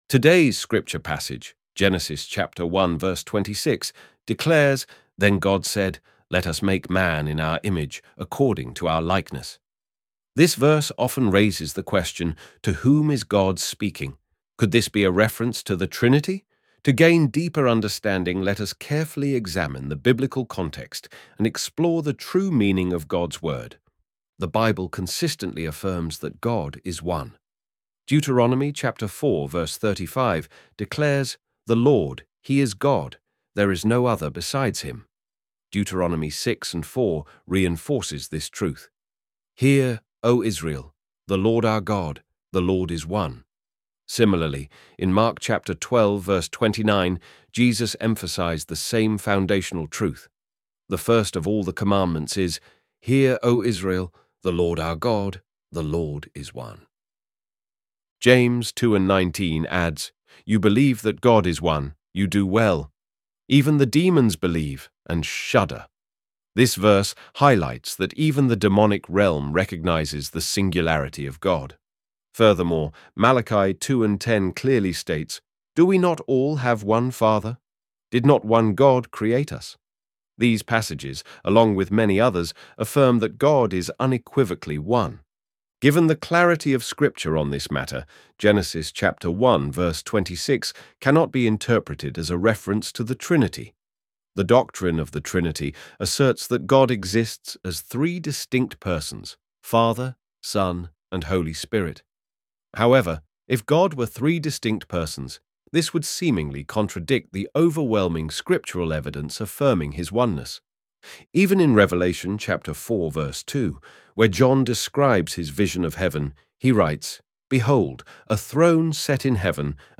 ElevenLabs_Who_is_God_speaking_too_.mp3